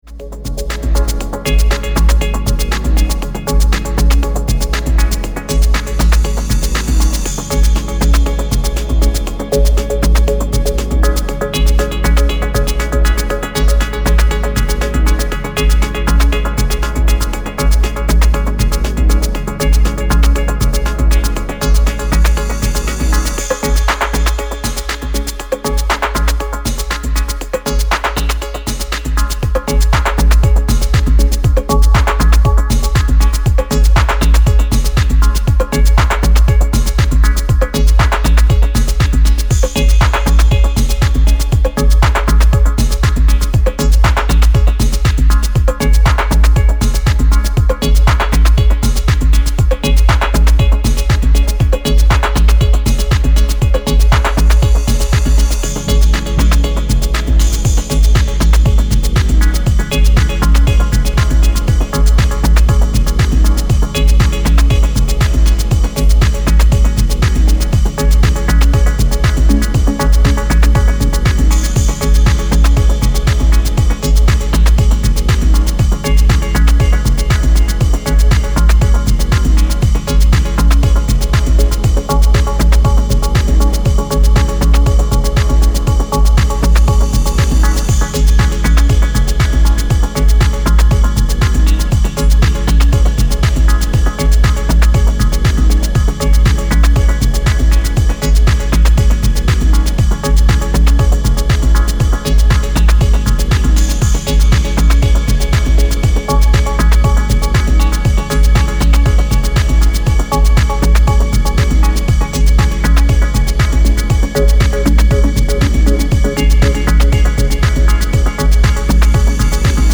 Deep House
Mix CD